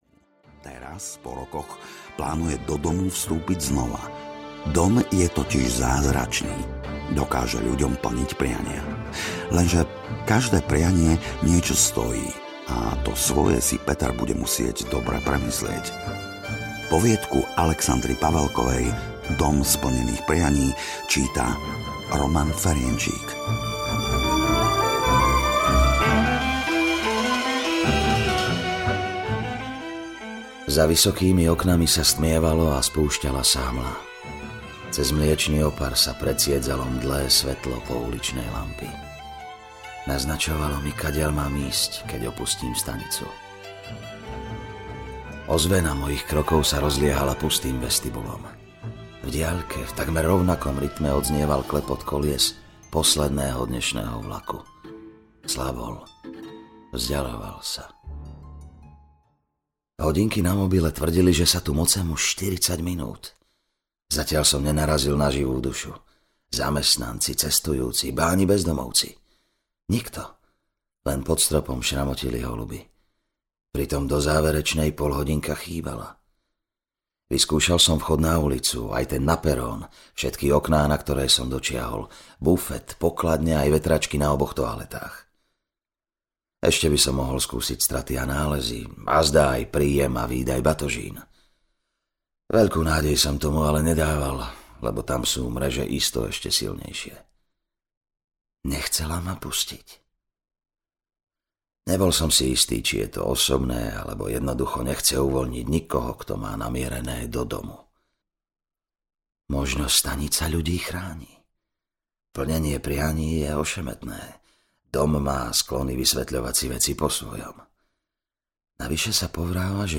Slovensko Fantastické audiokniha
Ukázka z knihy
Potešte svoje ucho krásnou hudbou.